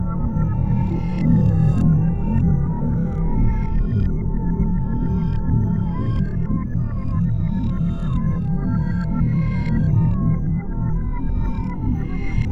portalEnergySound_nu.wav